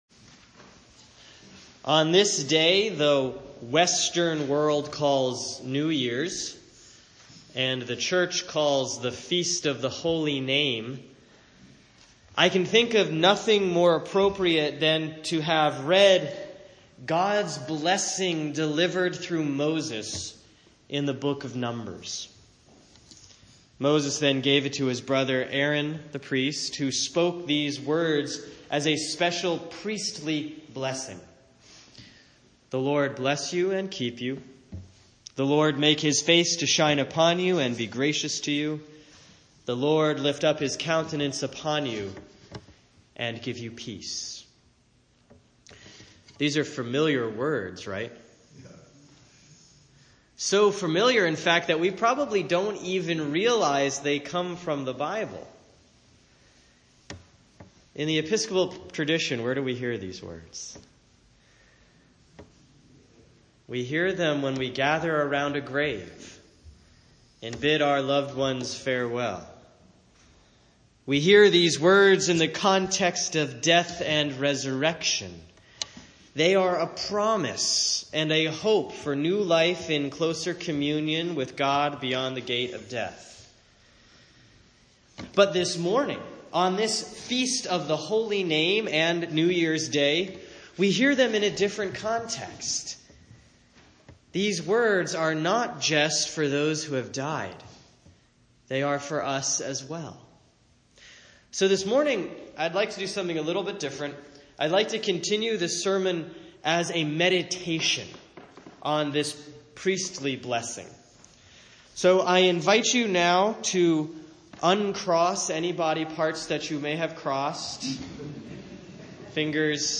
Sermon for Sunday, January 1, 2017